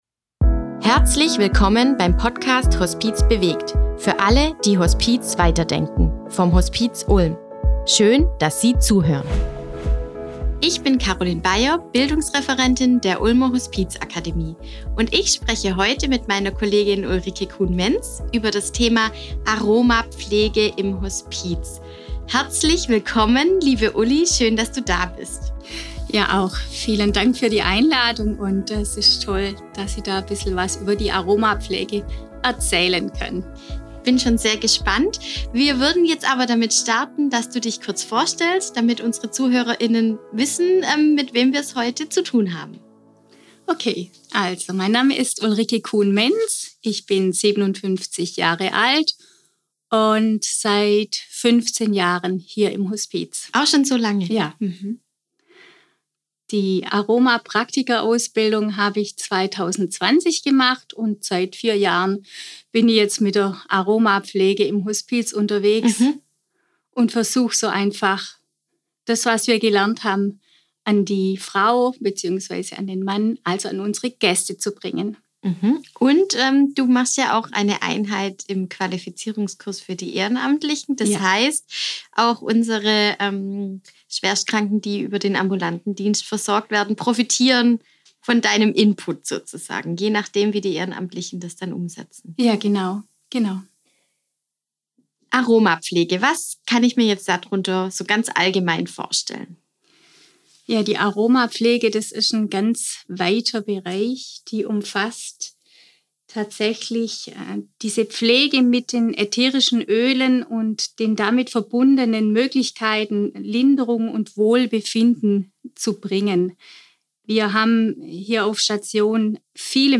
unterhält sich mit ihrer Kollegin